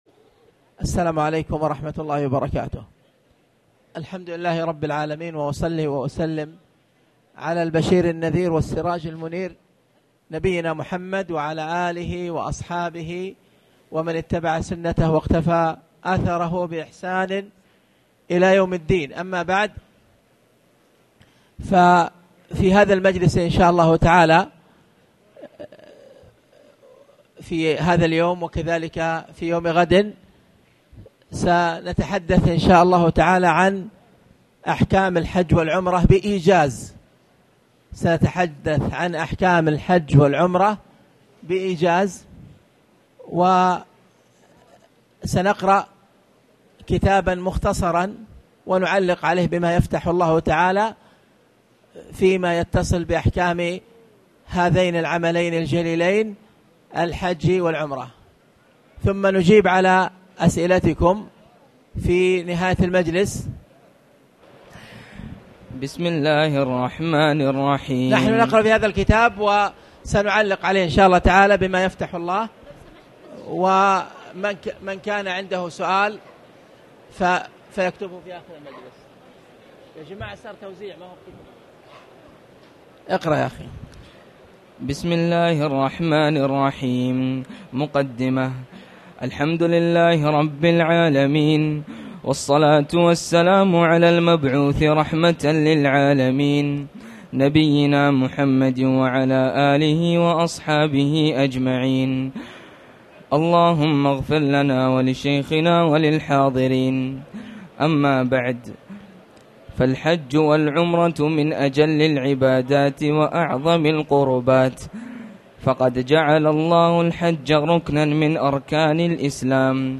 تاريخ النشر ٥ ذو الحجة ١٤٣٨ هـ المكان: المسجد الحرام الشيخ